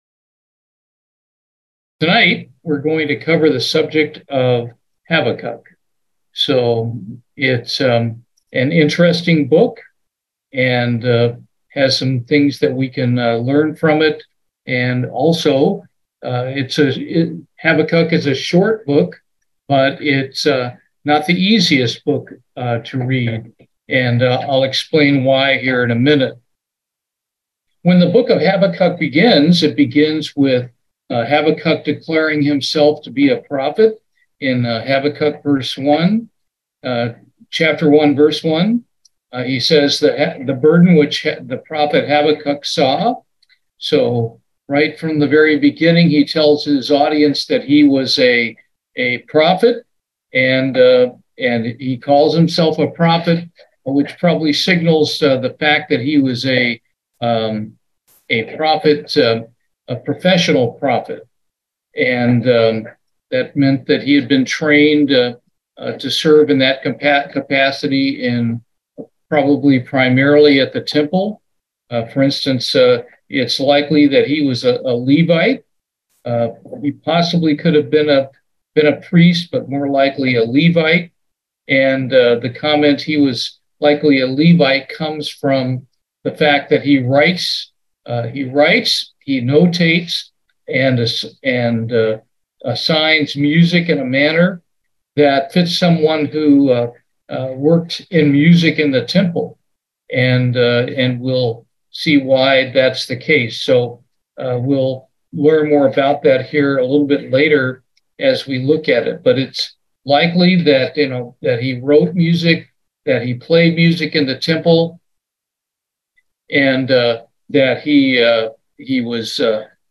Bible Study, Habakkuk